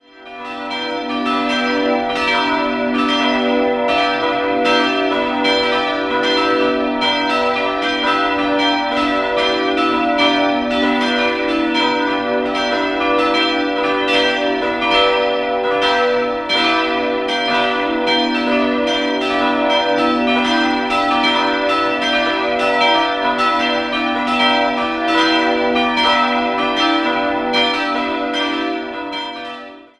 Idealquartett: h'-d''-e''-g'' 1 958 wurden die beiden größeren Glocken von Friedrich Wilhelm Schilling in Heidelberg gegossen, 1975 kamen aus der Heidelberger Glockengießerei die beiden kleinen hinzu. Diese vier Läuteglocken sind Teil eines insgesamt 49-teiligen Glockenspiels.